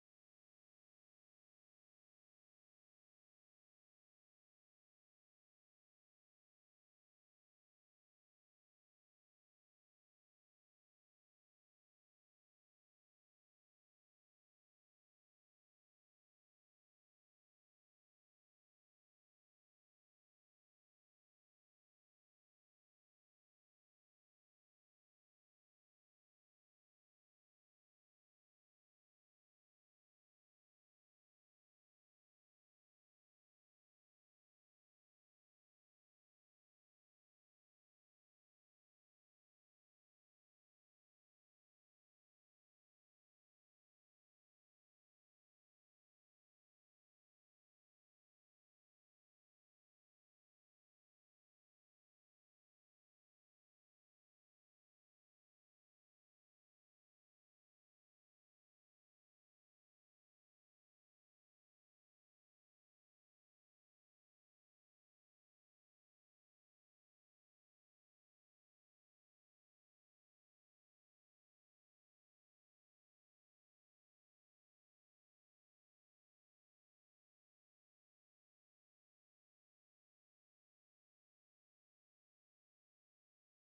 For the complete audio recordings of Rancho Santanta, and all future conference recordings click here.